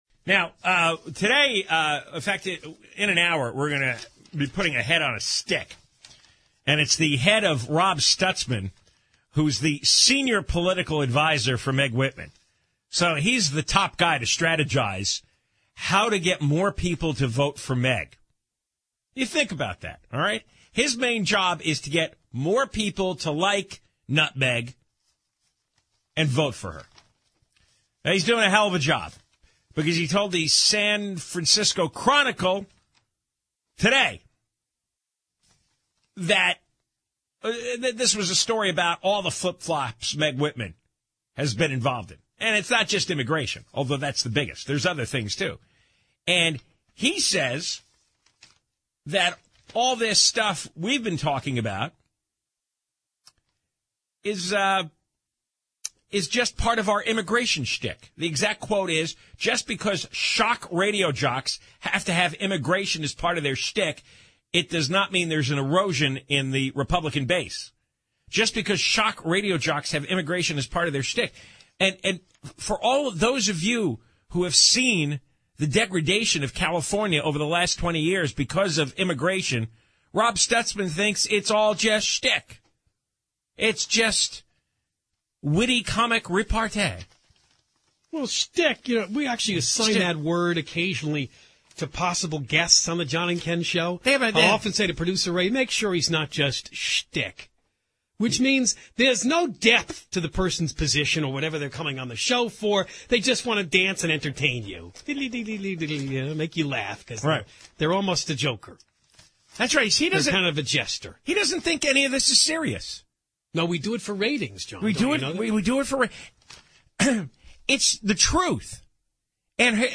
here’s John and Ken explaining the real meaning of “shtick” — go to 1:27 in the clip to hear it. J&K’s point is that if Whitman can switch on such big issues as illegal immigration and suspending the AB 32 global warming law, how can you trust her on anything else?